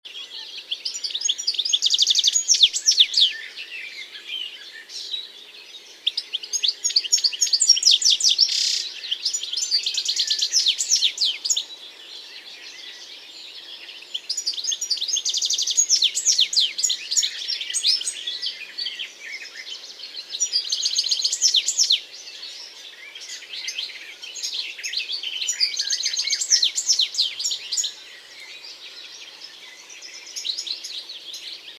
goldfinch-song.mp3